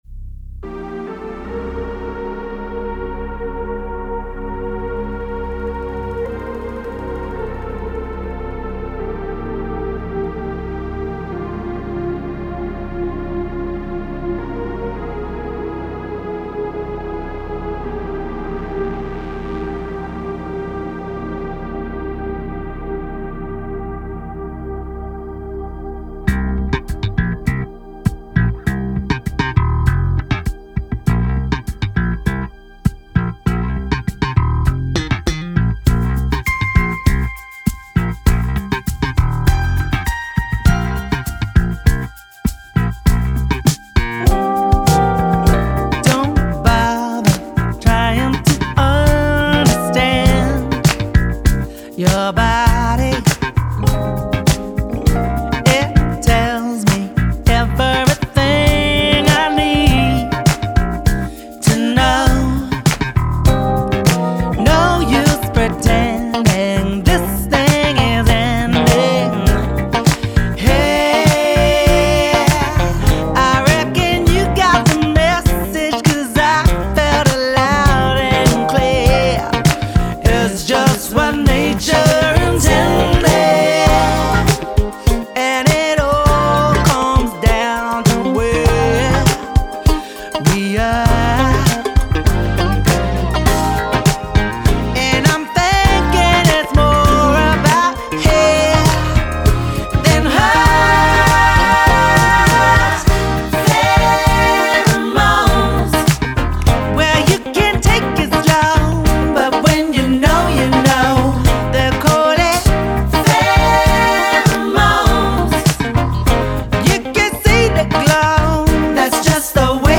Genre : Electro Funk, Soul, RnB.